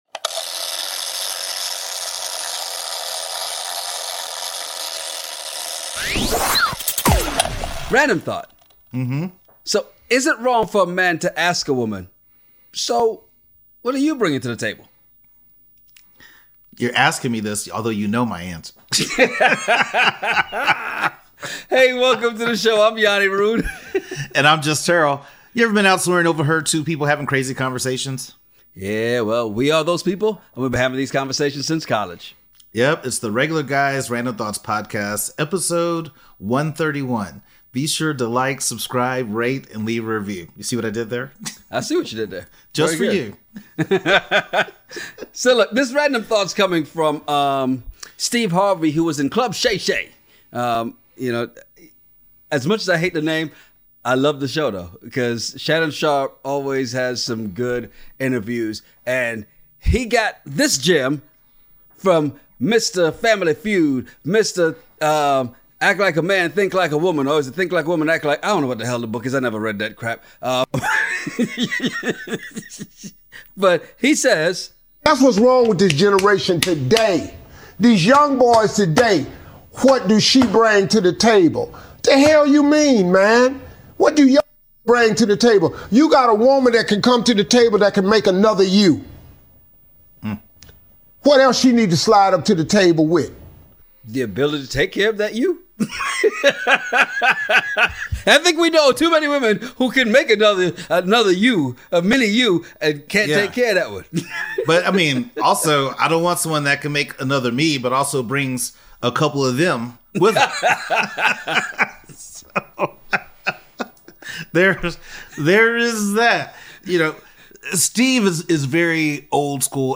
Is it wrong for men to ask what a woman is bringing to the table? 00:00 Intro 00:34 Steve Harvey 10:40 Dillon The Villain vs LeBron 19:34 Zombied vs Ghosted Ever been somewhere and overheard two guys having a crazy conversation over random topics? Well we are those guys and we have been having these conversations since college.